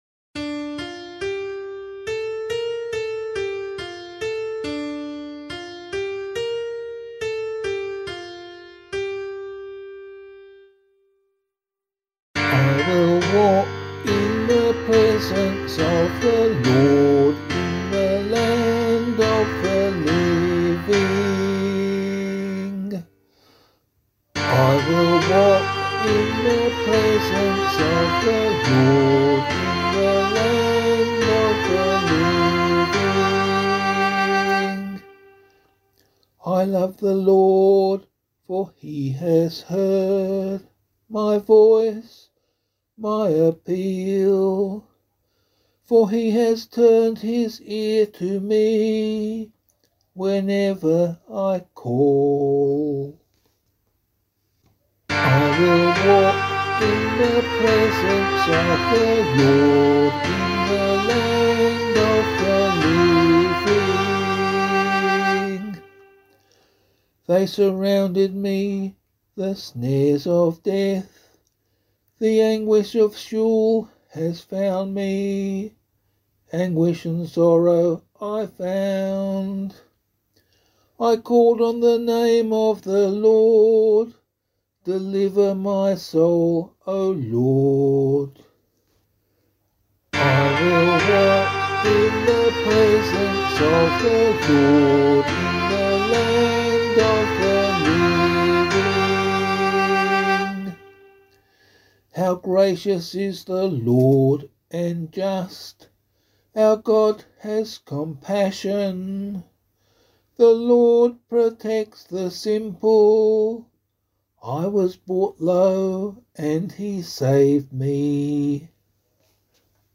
LiturgyShare Response with Meinrad Psalm Tone and Abbey Text